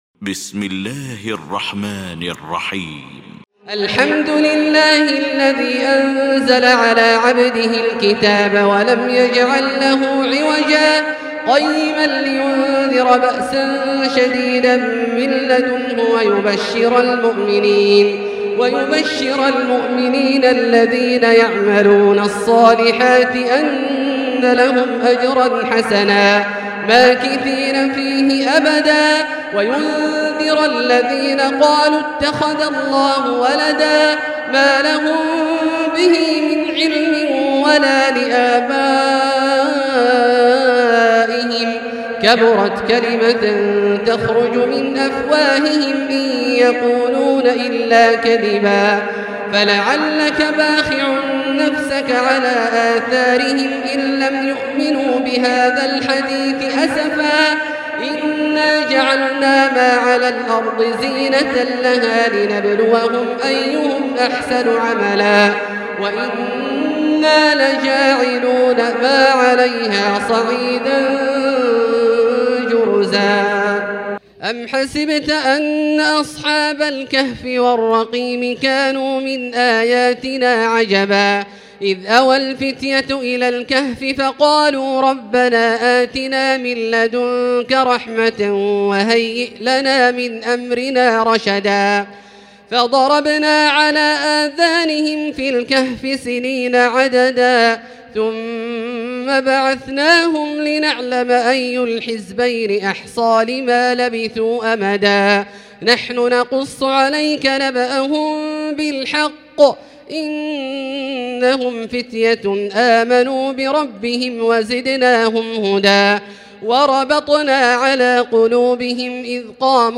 المكان: المسجد الحرام الشيخ: سعود الشريم سعود الشريم فضيلة الشيخ عبدالله الجهني فضيلة الشيخ ماهر المعيقلي الكهف